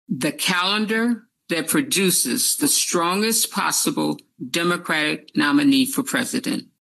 MINYON MOORE IS CHAIR OF THE GROUP THAT WILL MAKE THE DECISION AND SHE SAYS THEY ARE LOOKING FOR ONE THING.